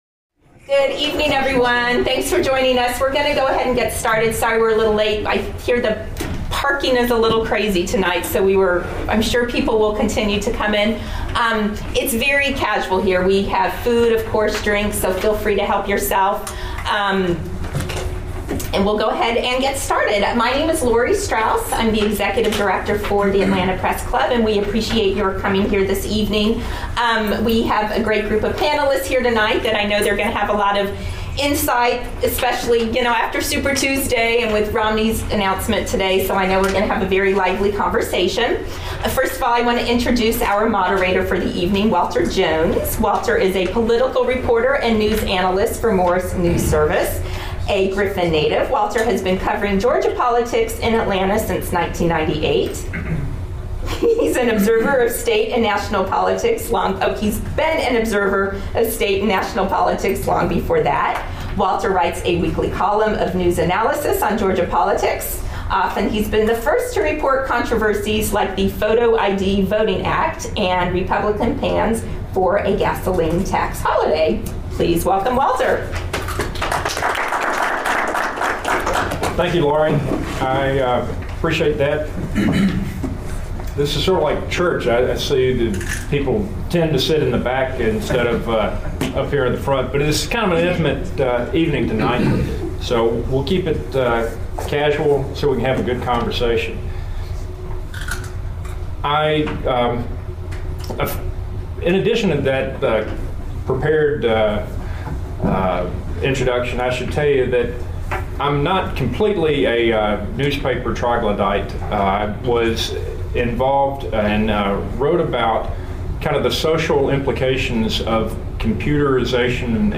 The Atlanta Press Club hosts monthly forums that feature local and national newsmakers tackling the industry's most pressing issues.
Please join us in a panel discussion as we look at some of these new methods and talk about new media is affecting the campaigns.